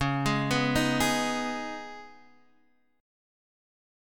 C#7sus2 chord